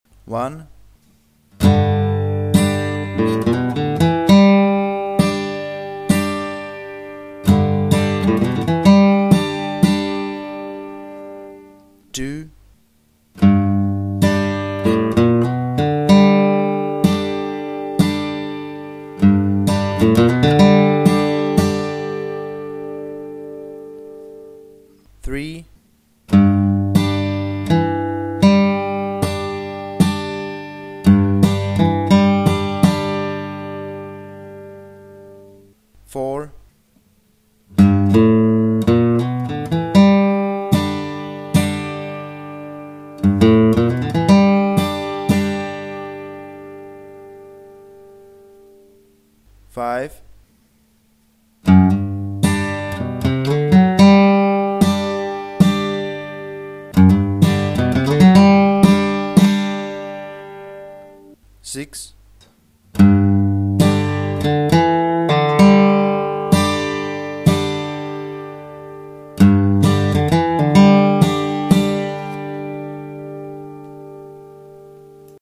Žánr: Blues.